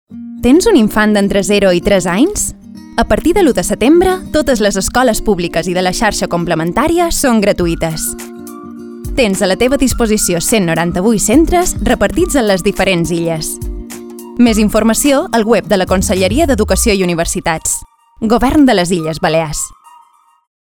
Arxiu Multimedia Falca Escoletes (.mp3)